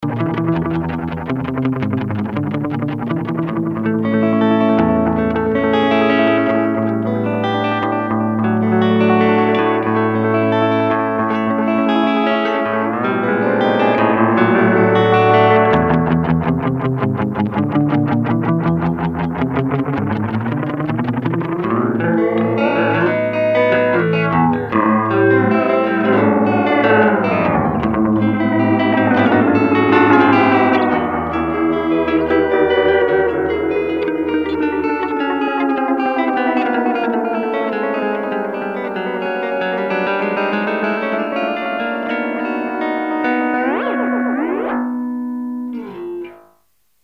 Delay machine (197x)
ac SOUND analog generation based on Bucket brigade chips BBD MN3005 + MN3101.
2- DELAY TIME: range up to 300ms, short for flanging effect
demo guitar demo
- warm
REVIEW: "Love it: Good defined solid sound. Worth every euro or turkish lira or whatever ."